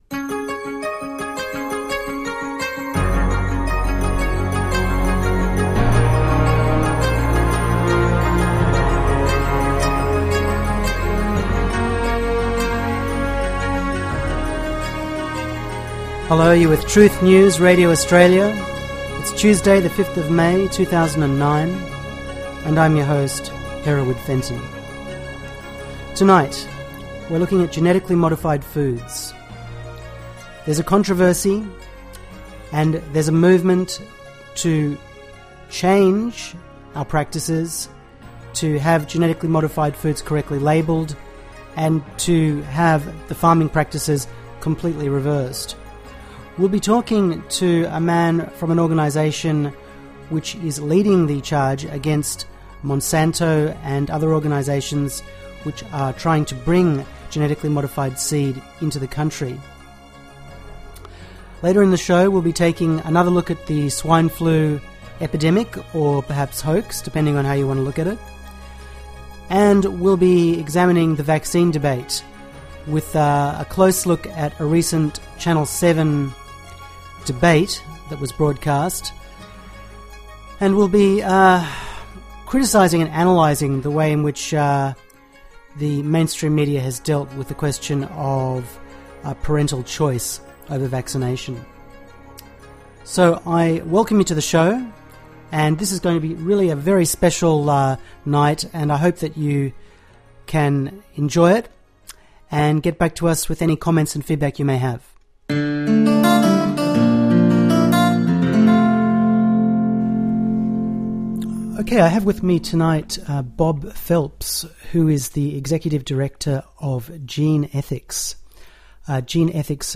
extended interview